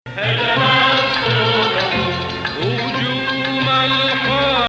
Hijaz 3